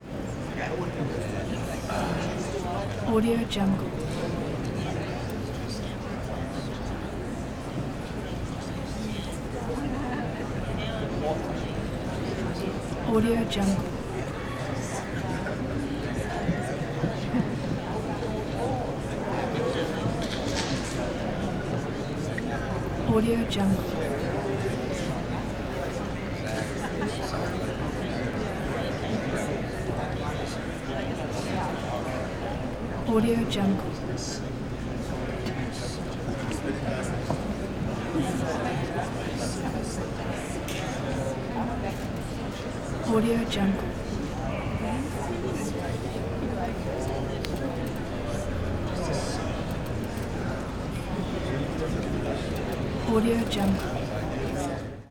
دانلود افکت صوتی جمعیت در سالن سینما منتظر شروع فیلم
Crowds Theater Theatre500 PeopleMIllingWaiting royalty free audio track is a great option for any project that requires human sounds and other aspects such as a theatre, people and milling.
Sample rate 16-Bit Stereo, 44.1 kHz